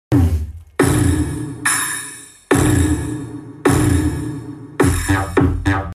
Dual 5 Inches Speaker Bass Sound Effects Free Download